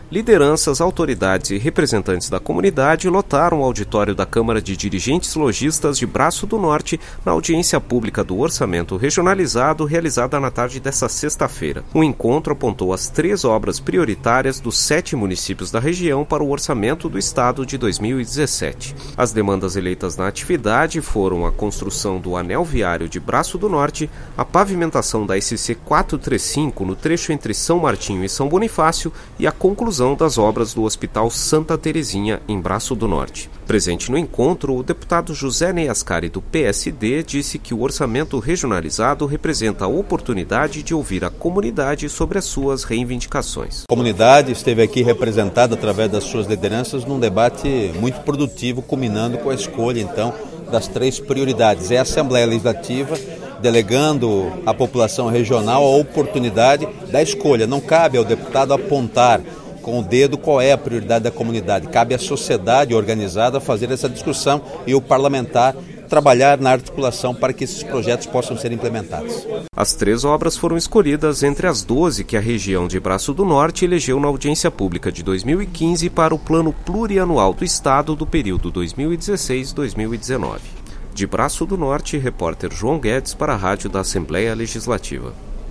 Entrevista com:
- deputado José Nei Ascari (PSD).